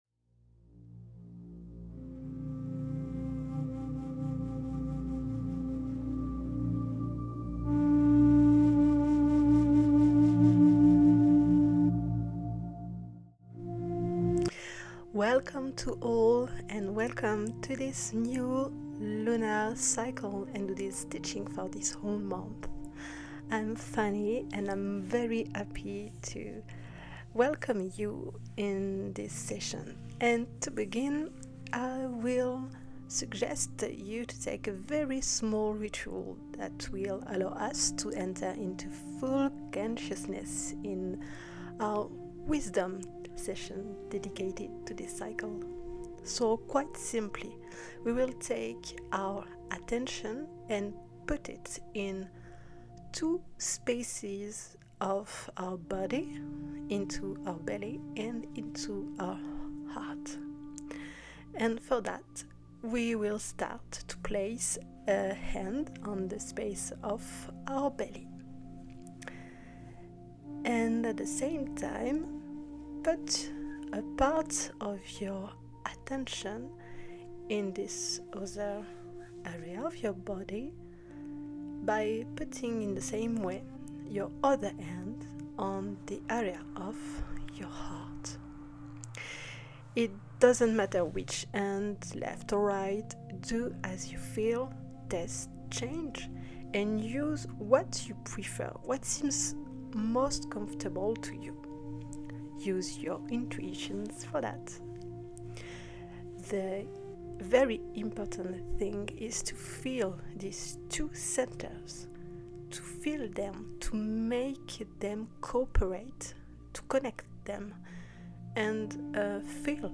To begin, if you’re ok, I suggest that you consciously open this sacred workspace with a short breathing ritual. I’ll guide you in the following audio:
Meditation-ENG-1.mp3